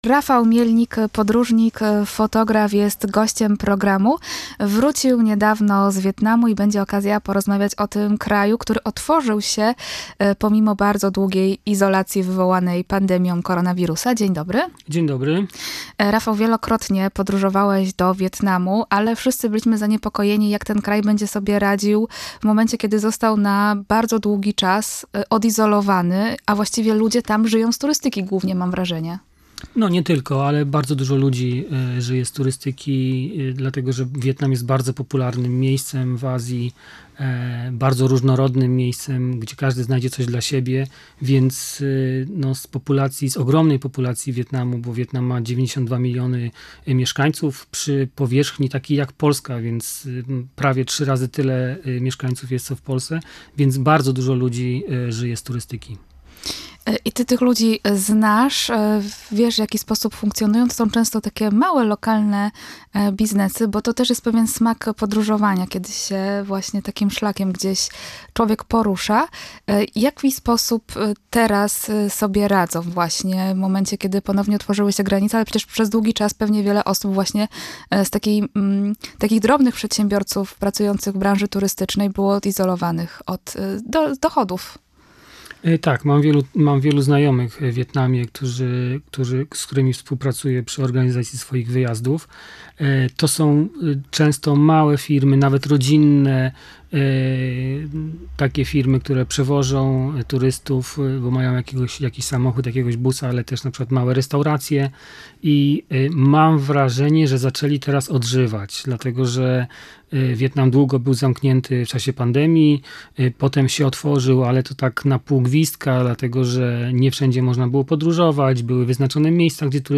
rozmowa-wietnam.mp3